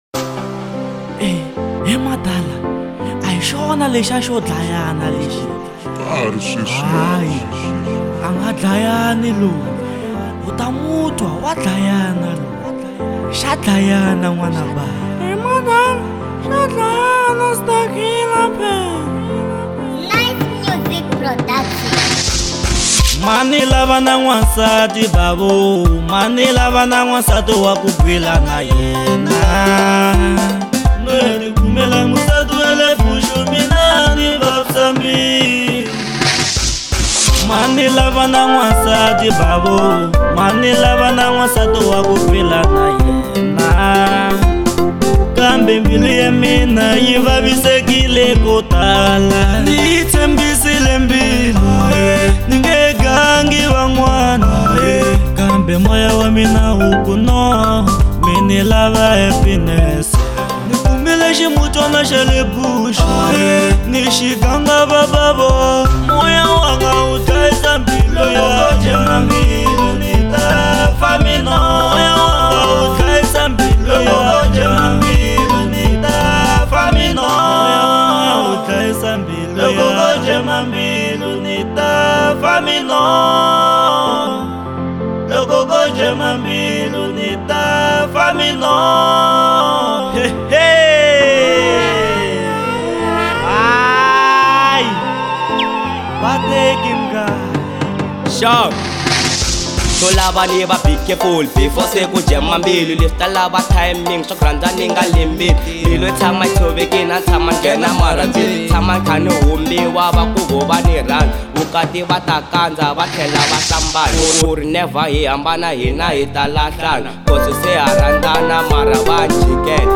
02:36 Genre : Marrabenta Size